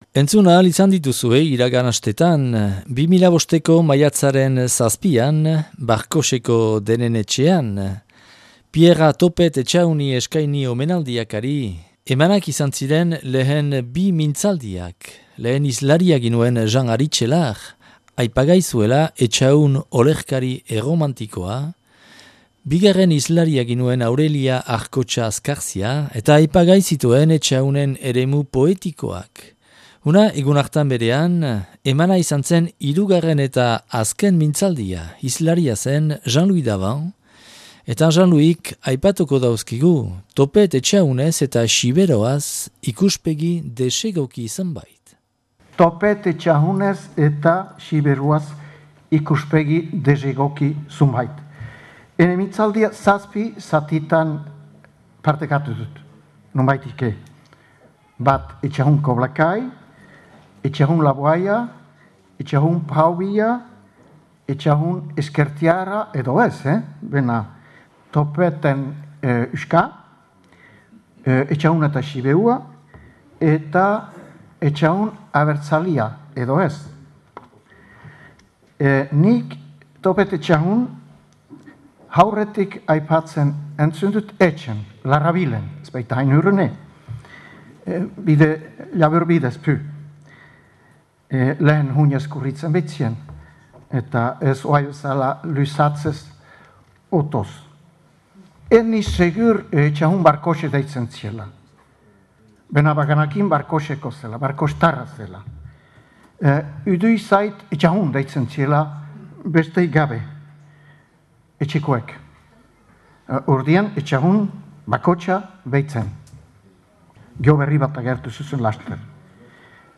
(Grabatua Barkoxen 2005. Maiatzaren 7an).